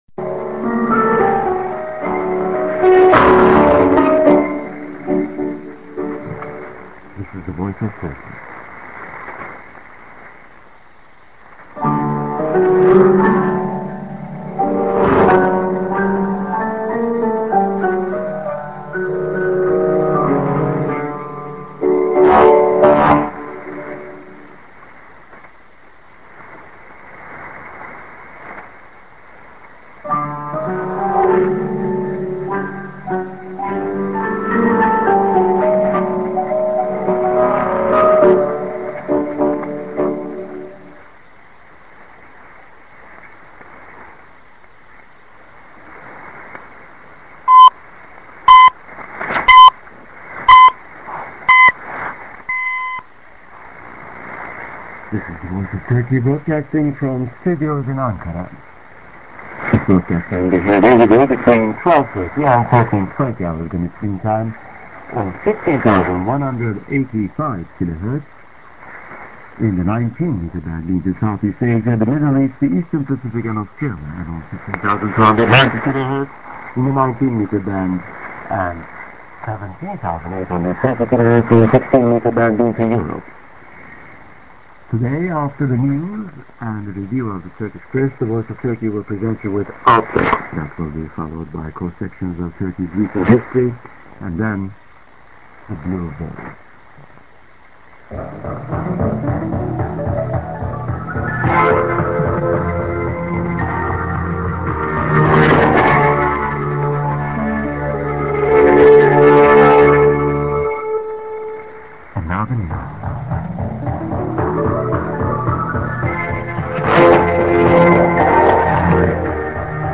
St. IS+ID(man)-> 47":TS-> ID+SKJ(man)->